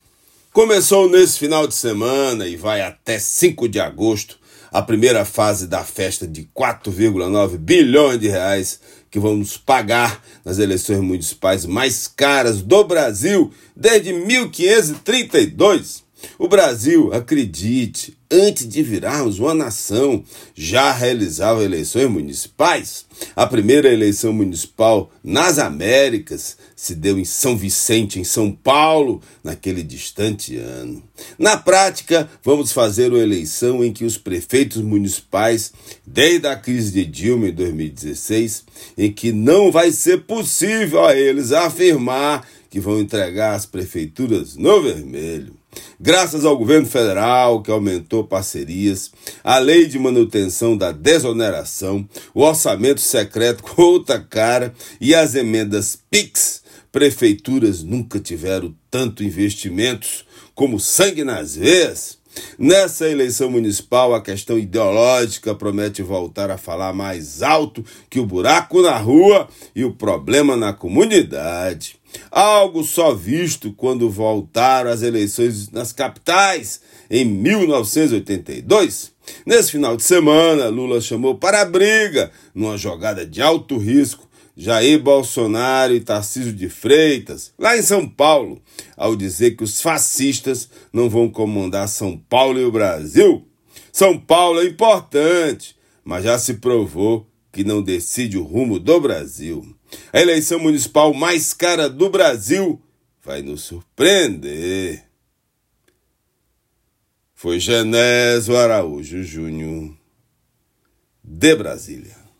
Comentário desta segunda-feira
direto de Brasília.